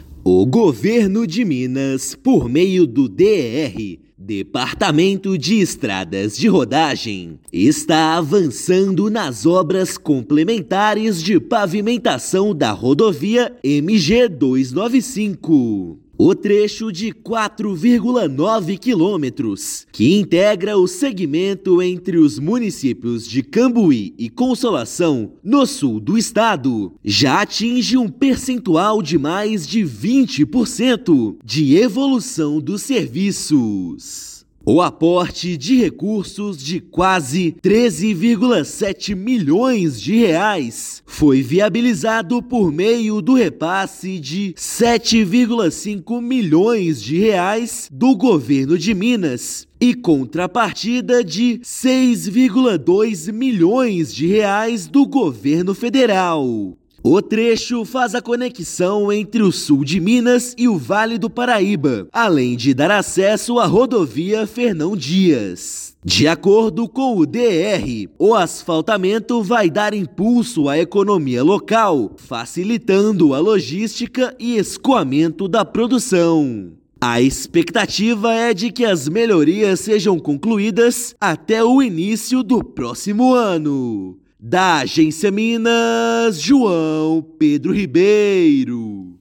Trecho de 4,9 quilômetros da rodovia MG-295 está sendo pavimentado e a expectativa é que seja concluído até início do próximo ano. Ouça matéria de rádio.